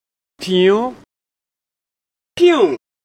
Comedy Sound Effect 11
Piuuu-Disappearing-trend-comedy-sound.mp3